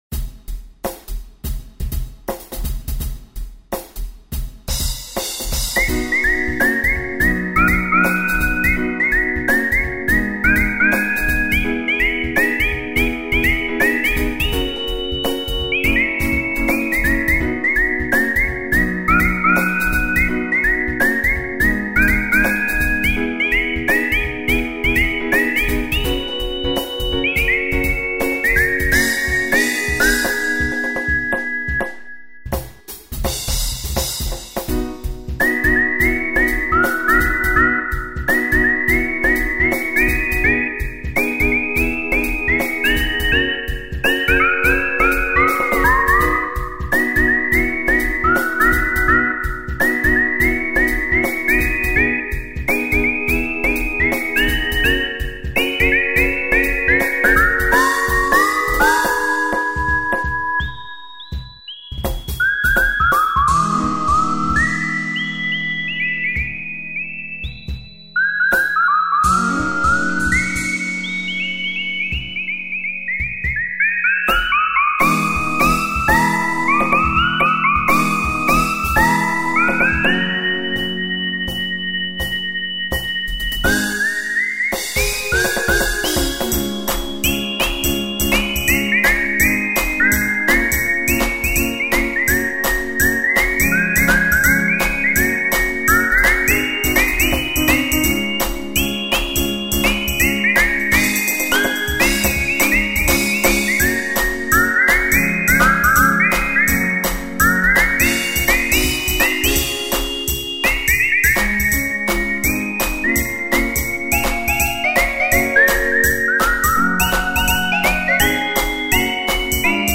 一応、これが僕の口笛を使って作った”kutibue song”です。